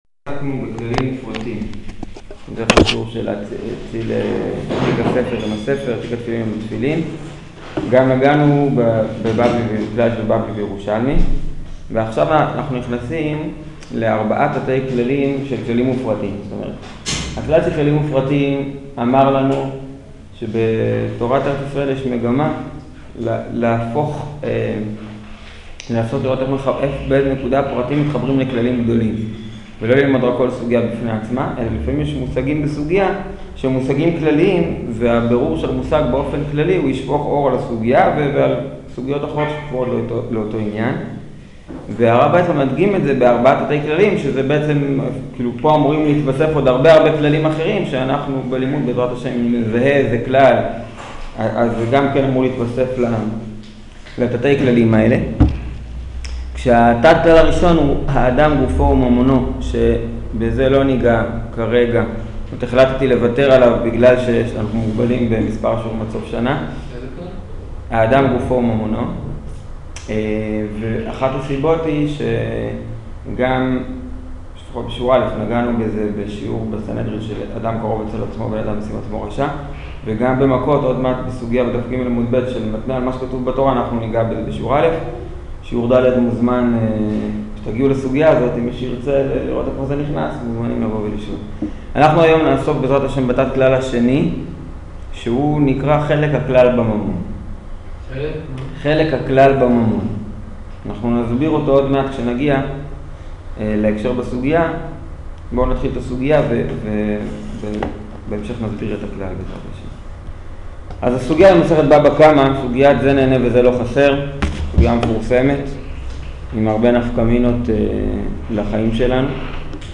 שיעור חלק הכלל בממון